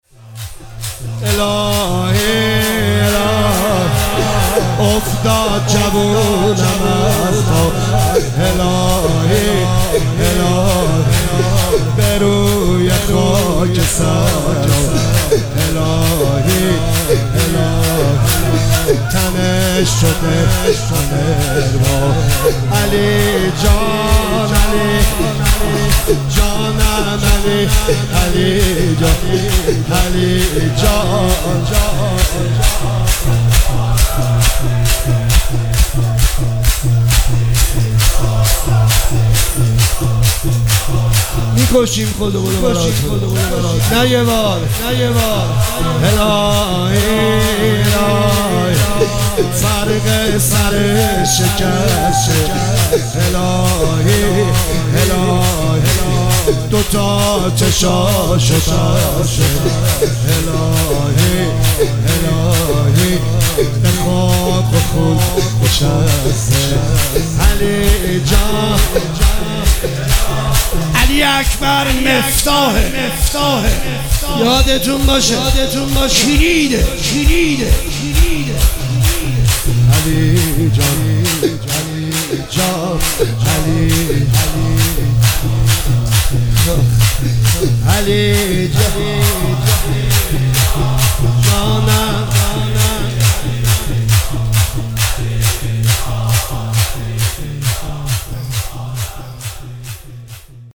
مداحی شور
هشتم محرم 1404